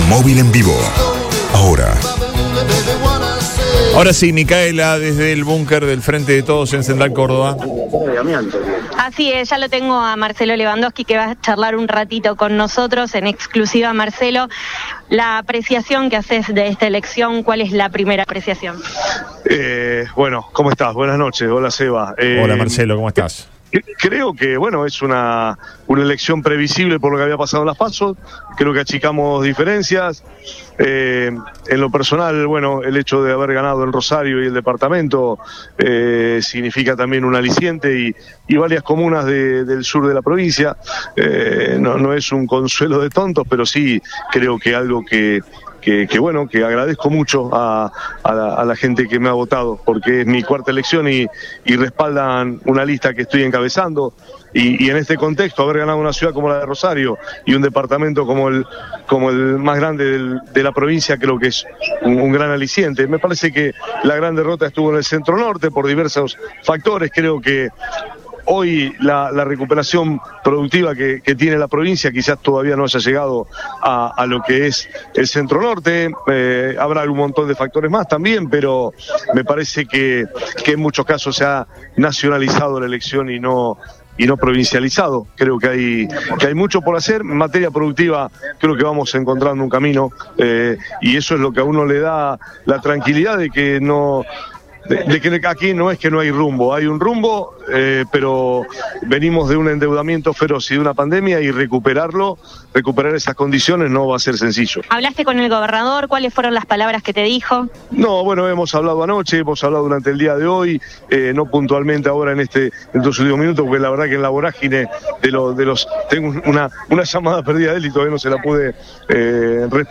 EN RADIO BOING
“Es una elección previsible, creo que achicamos diferencias. El hecho de haber ganado en Rosario significa mucho”, comenzó en diálogo con el móvil de Radio Boing.